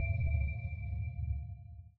sonarTailSuitMedium2.ogg